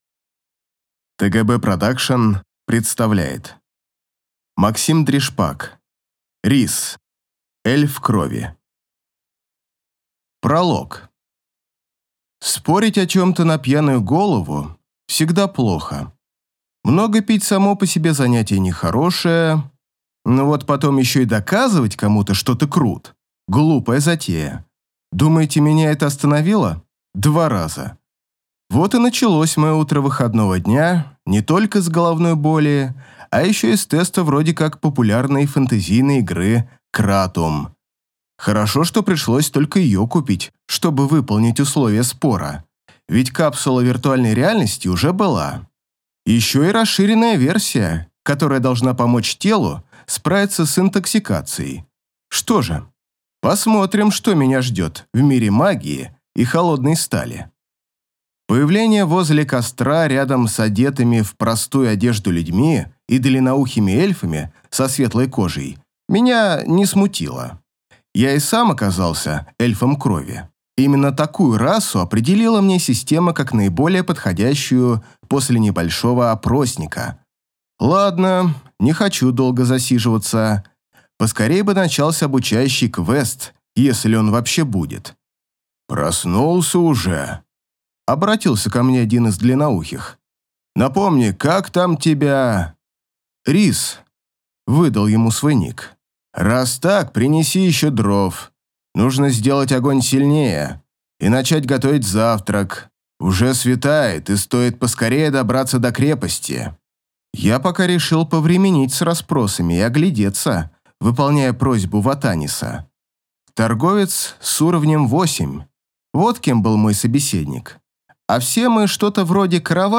Аудиокнига Рисс. Эльф крови | Библиотека аудиокниг